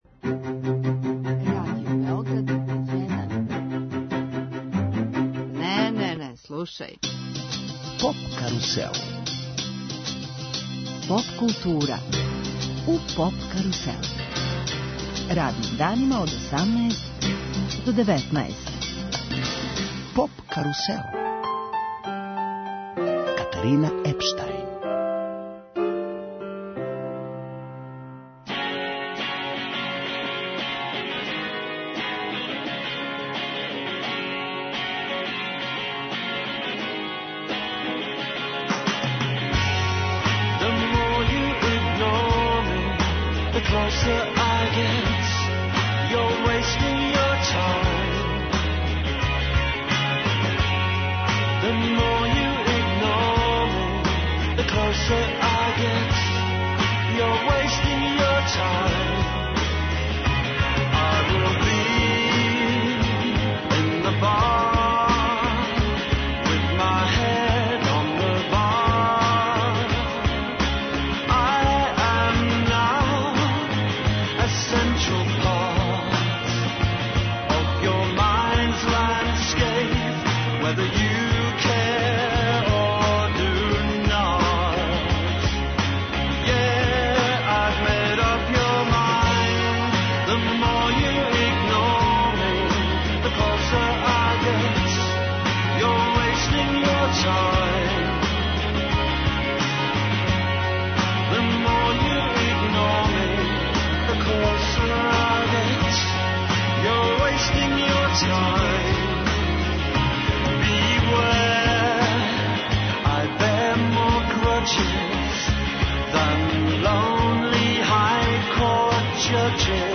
Гост емисије Неле Карајлић, композитор, певач и писац, поводом великог београдског концерта.